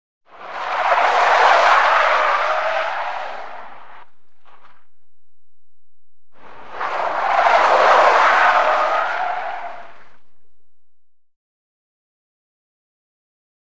Dirt Skids; Long Squealing Skids On Smooth Unpaved Surface, Dirt Grinding Under Tires. Medium Perspective.